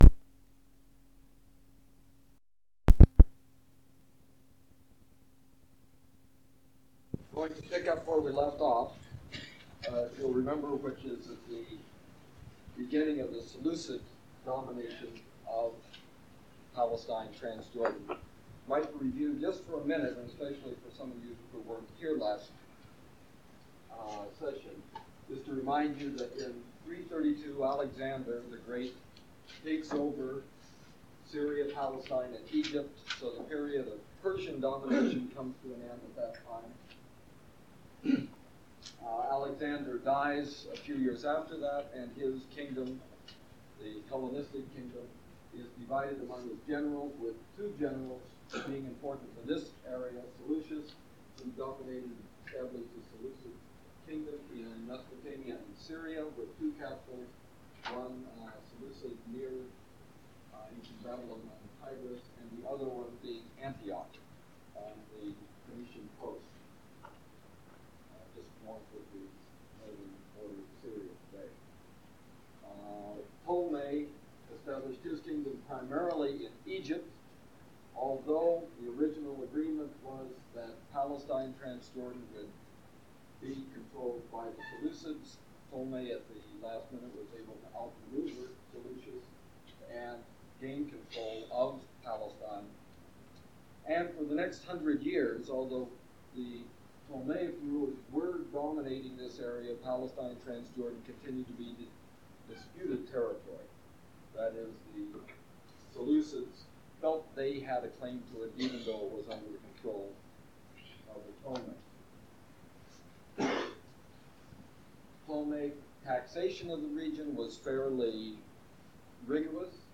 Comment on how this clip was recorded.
Format en audiocassette ID from Starchive 417975 Tag en Excavations (Archaeology) -- Jordan en Bible -- Antiquities en Archaeology Item sets ACOR Audio-visual Collection Media Arch_Bible_07_access.mp3